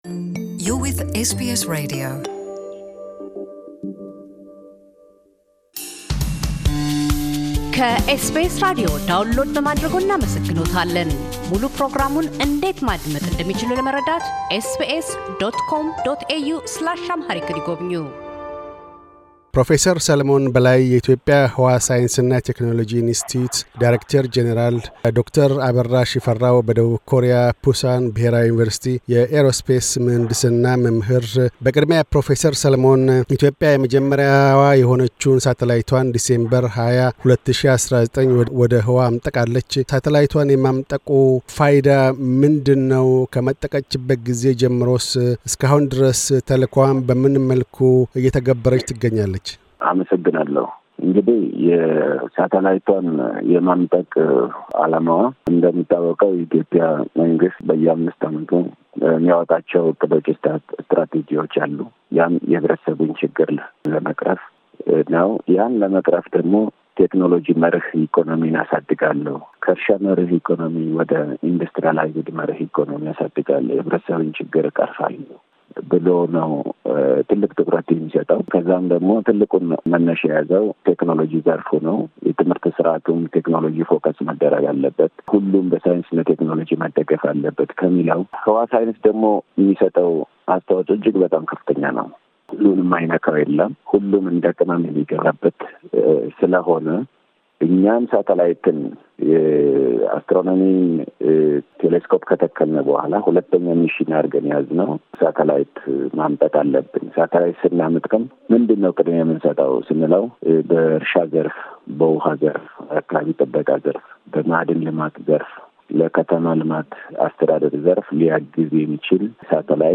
ቃለ ምልልስ